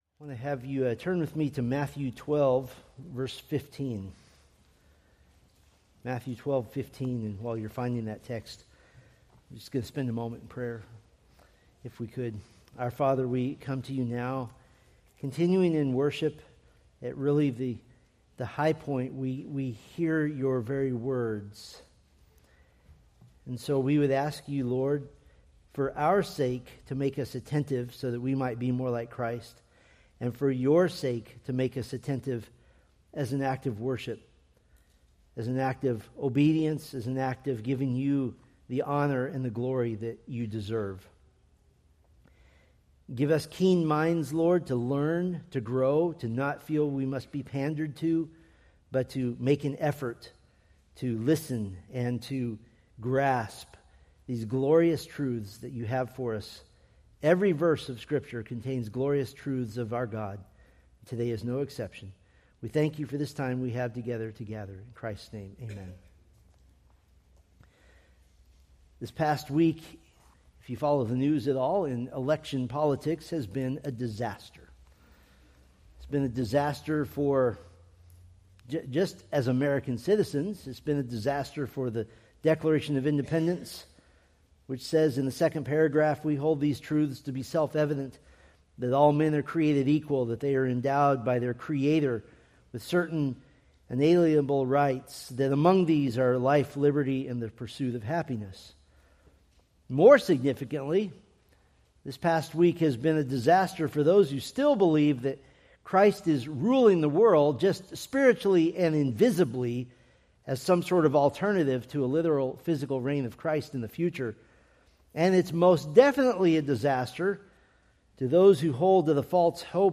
Preached November 9, 2025 from Matthew 12:15-21